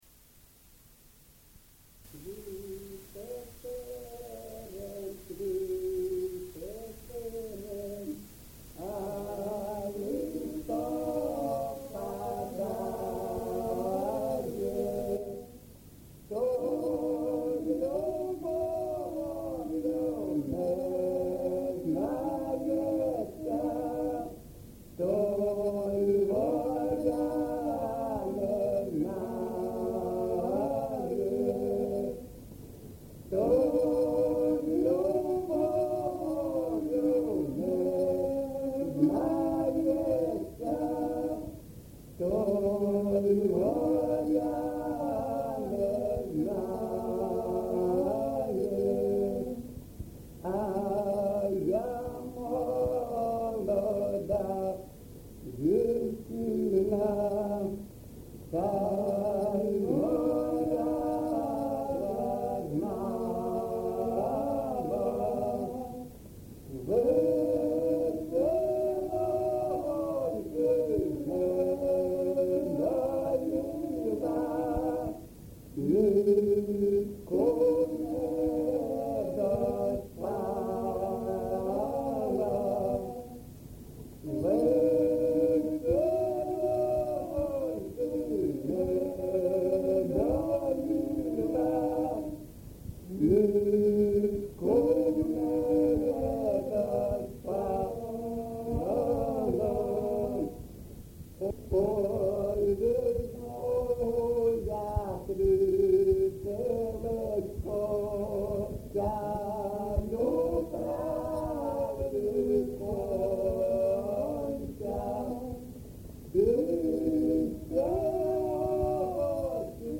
ЖанрПісні з особистого та родинного життя
Місце записум. Часів Яр, Артемівський (Бахмутський) район, Донецька обл., Україна, Слобожанщина